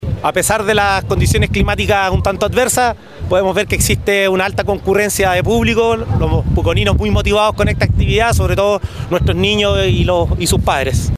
“A pesar de las condiciones climáticas un tanto adversas, podemos ver que existe una alta concurrencia de público, los puconinos siempre son muy motivados con estas actividades, sobre todo nuestros niños y sus padres”, comentó en el ingreso al Parque Nacional Villarrica